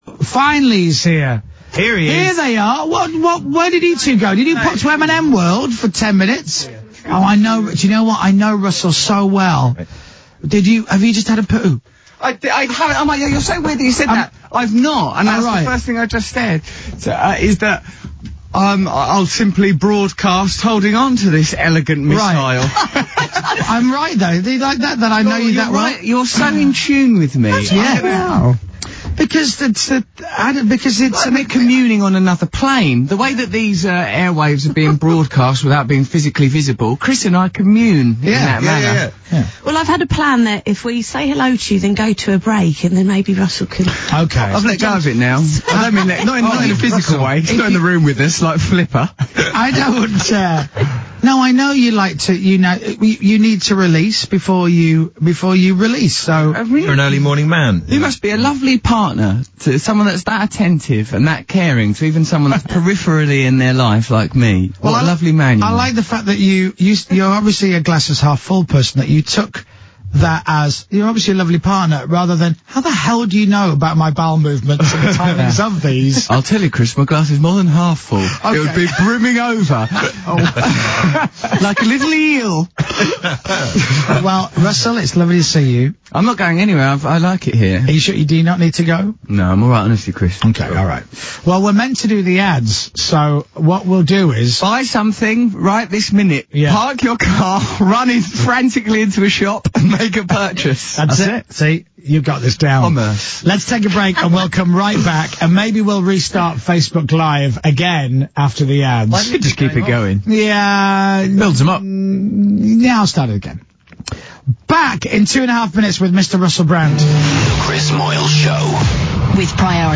Russell Brand on Radio X with Moyles - March 2017